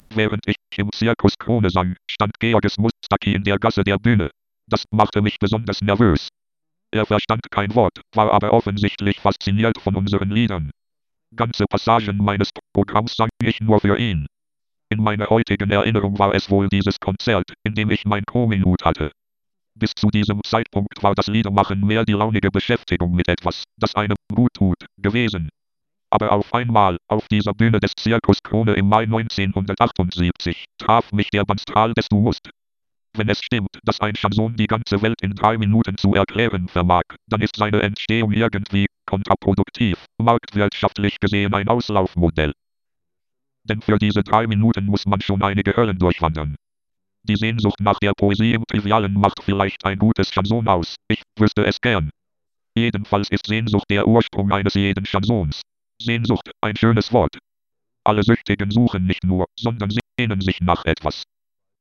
gleicher Text in 3 Sprecherversionen
Die mit Nachsicht zu behandelnde Vorlesemaschine
MP3-Datei, 999.424 Byte, mono, 1'01
muggscan.mp3